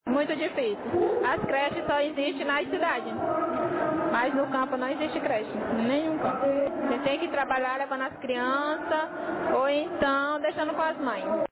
Maranhão.mp3